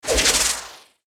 archer_skill_ancleshot_01_turn.ogg